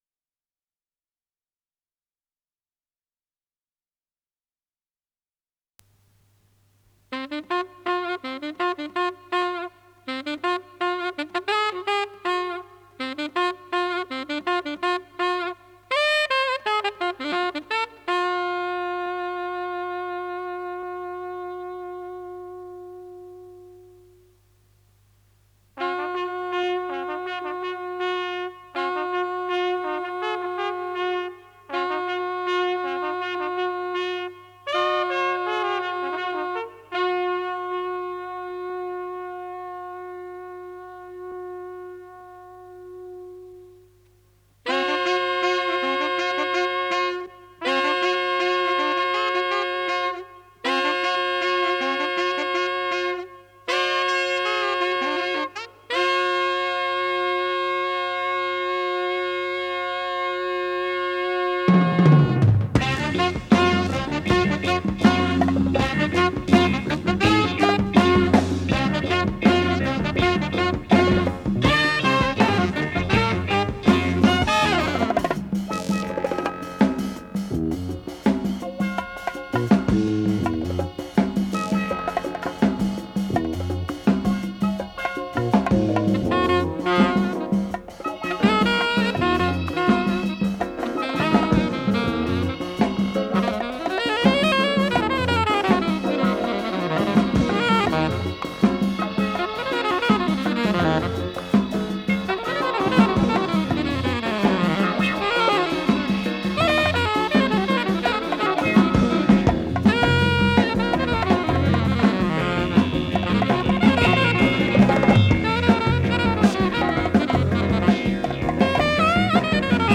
с профессиональной магнитной ленты
ПодзаголовокПьеса для джаз-ансамбля, фа мажор
ВариантДубль моно